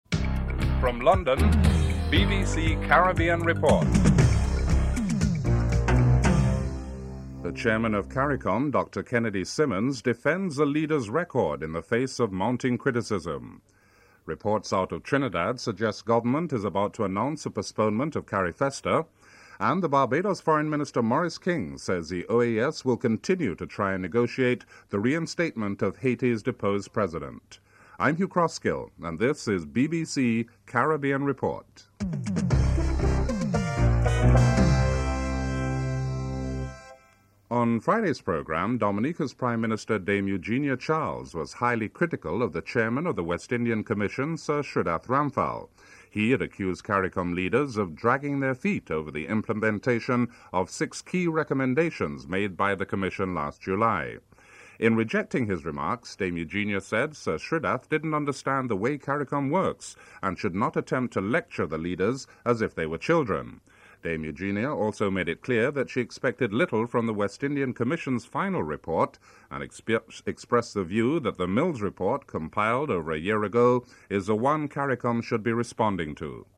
7. Interview with Dr. Kennedy Simmons on the issue of increasing frustration with CARICOM (05:08-07:29)
11. Report and interview with Barbados foreign minister, Morris King on the failure of the OAS sponsored meeting in Washington on President Aristide's possible return to office (11:51-13:36)